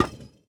Minecraft Version Minecraft Version 1.21.5 Latest Release | Latest Snapshot 1.21.5 / assets / minecraft / sounds / block / decorated_pot / insert_fail4.ogg Compare With Compare With Latest Release | Latest Snapshot
insert_fail4.ogg